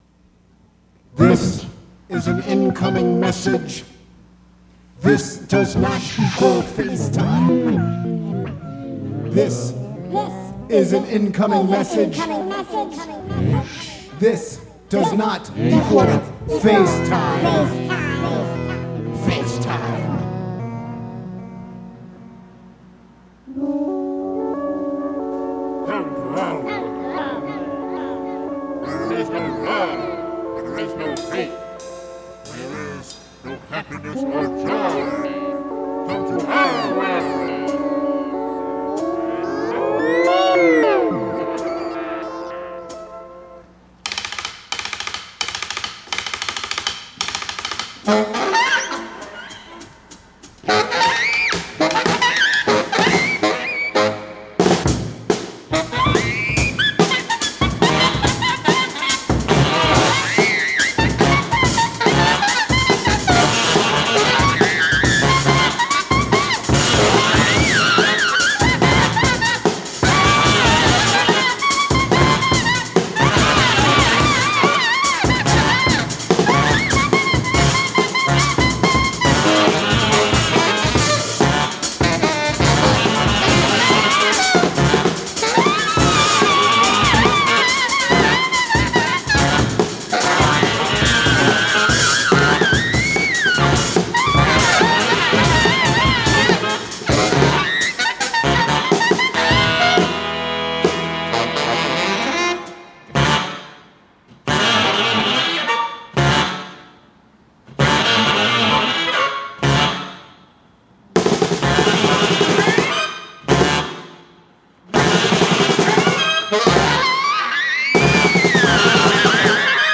Recorded live at the 39th Street loft, Brooklyn.
drums
alto saxophone
Â tenor saxophone
Stereo (Pro Tools)